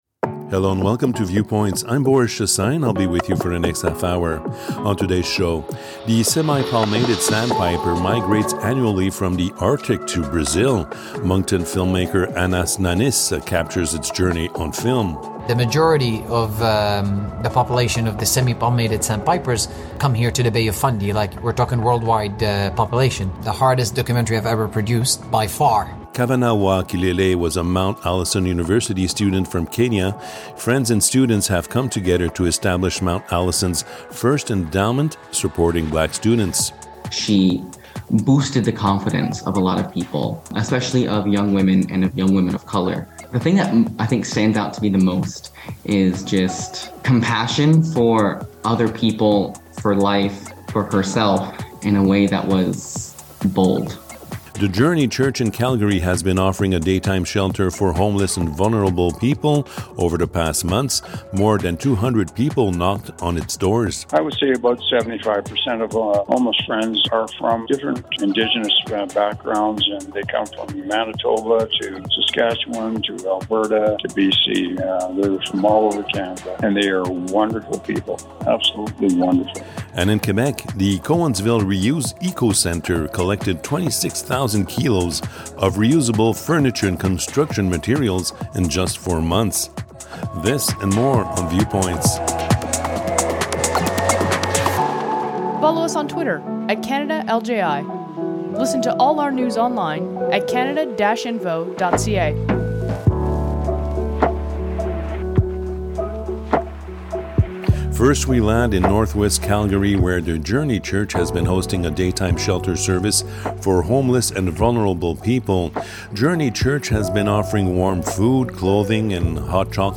Viewpoints, Ep.50 Viewpoints Ep.62, CRFC's national radio program.